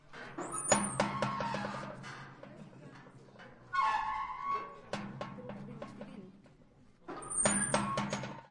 乌干达 " 金属厚重的地牢监狱门解锁打开关闭砰砰作响，舱门打开关闭滑行吱吱作响各种角度的声音
描述：金属重型牢牢监狱门解锁打开关闭嘎嘎声和舱口盖打开关闭幻灯片吱吱声各种外部视角onmic + bg宿舍厨房sounds1.wav
标签： 打开 关闭 舱口 幻灯片 金属 拨浪鼓 解锁
声道立体声